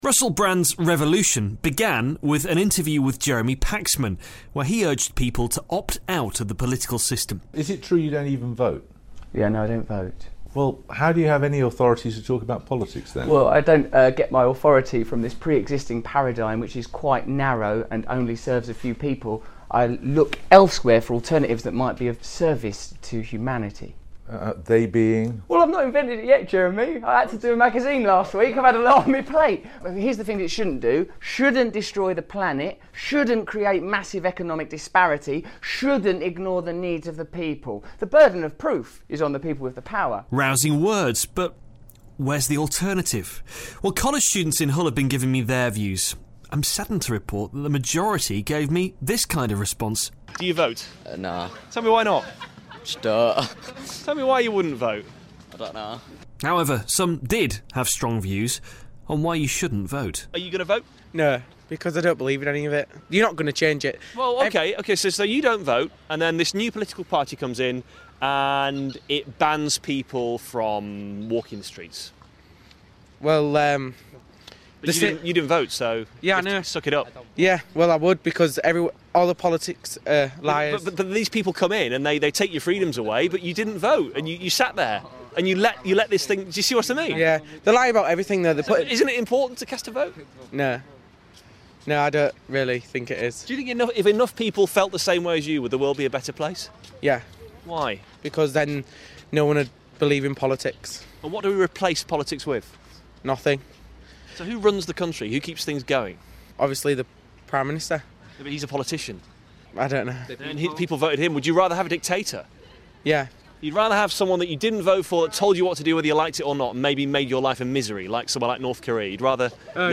Russell Brand says people should abstain from voting, to send a message of no confidence to politicians. College students from Hull have been telling me why they don't bother to vote.